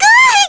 Miruru-Vox_Damage_kr_02.wav